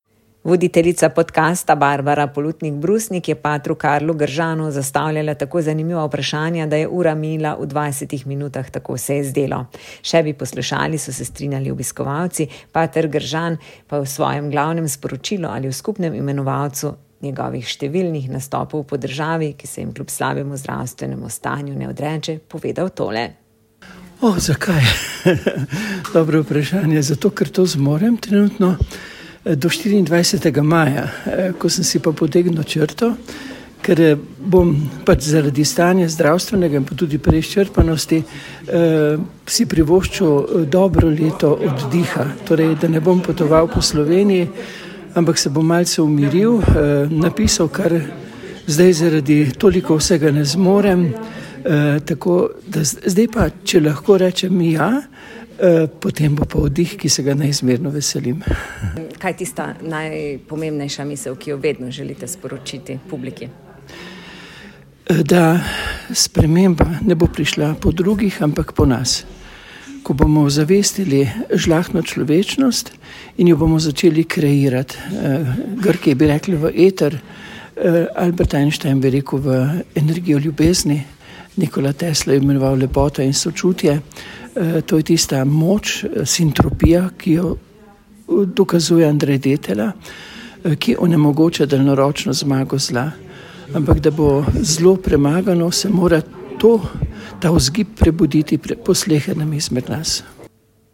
Sinoči je Koroški radio pred izbranim občinstvom posnel nov podkast